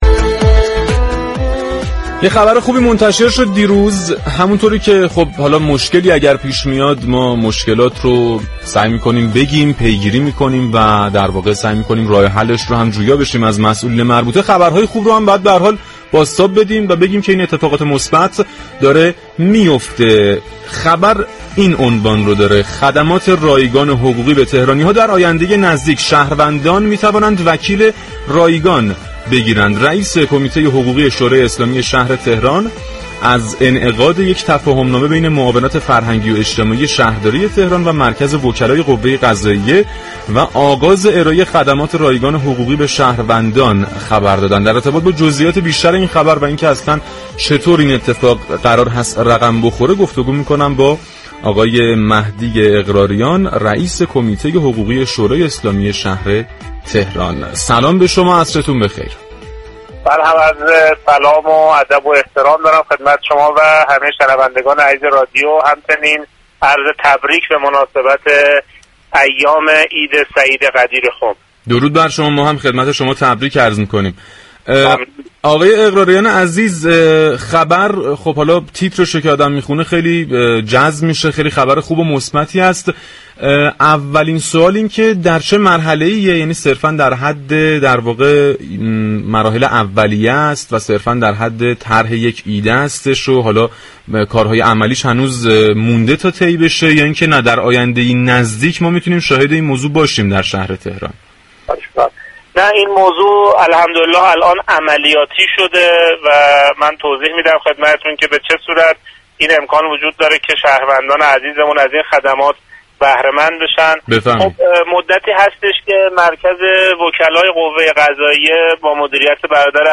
به گزارش پایگاه اطلاع رسانی رادیو تهران، مهدی اقراریان رئیس كمیته حقوقی شورای اسلامی شهر تهران در گفت و گو با «تهران من» اظهار داشت: مدتی است كه مركز وكلای قوه قضاییه، برای ارایه مشاوره رایگان به شهروندان روزهای یكشنبه در مساجد سراسر كشور جلساتی برگزار می‌كند.